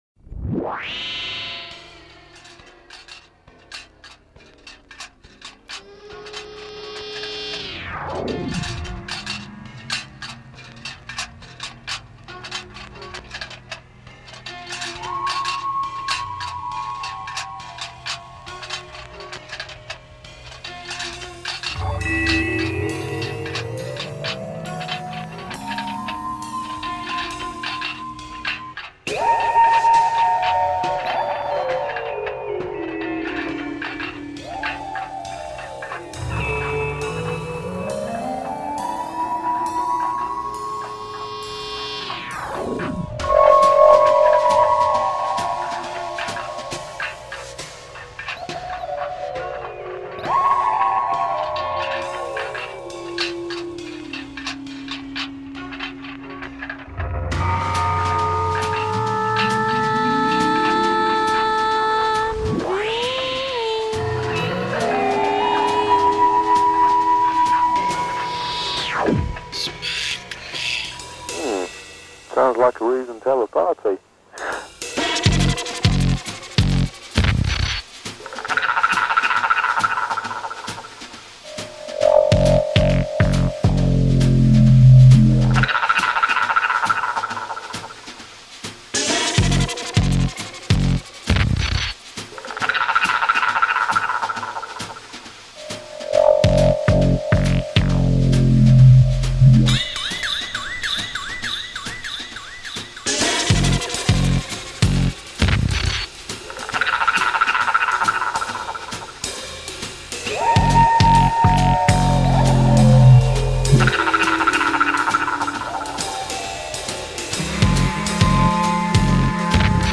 Ambient Beat